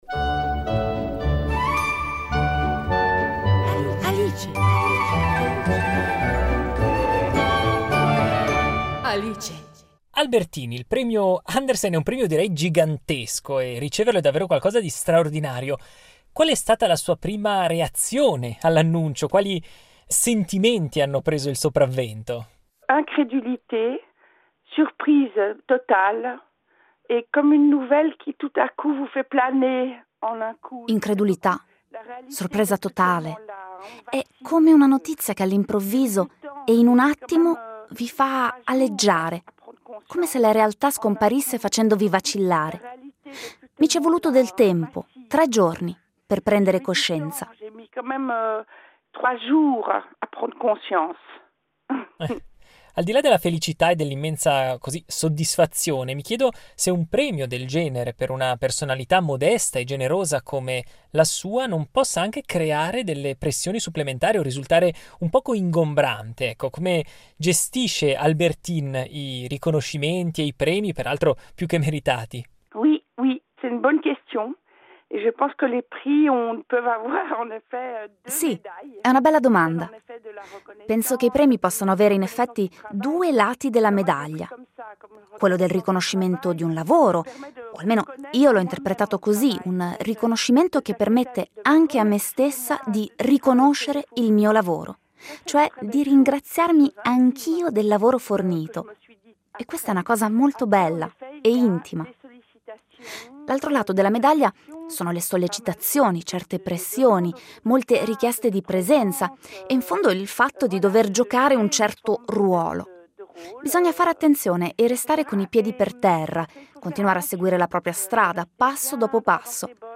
La ginevrina Albertine al microfono